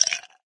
ice2.ogg